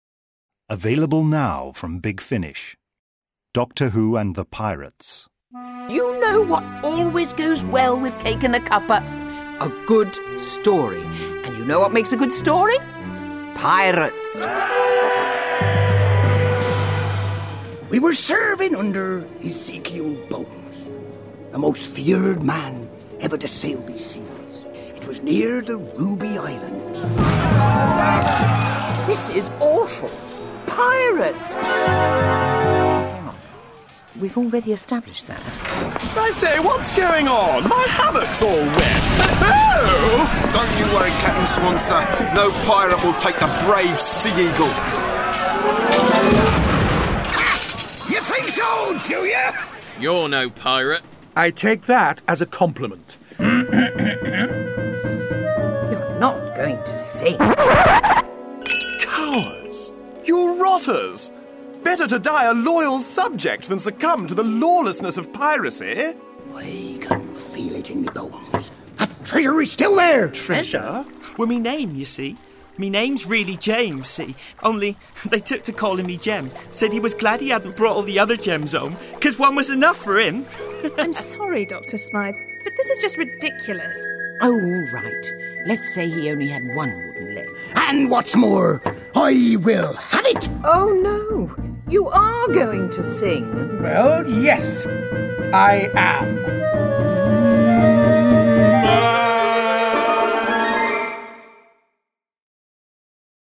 Award-winning, full-cast original audio dramas
All aboard me hearties for a rip-roaring tale of adventure on the high seas! There'll be rum for all and sea shanties galore as we travel back in time to join the valiant crew of the Sea Eagle, and meet a peripatetic old sea-dog known only as the Doctor.